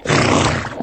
PixelPerfectionCE/assets/minecraft/sounds/mob/horse/hit1.ogg at mc116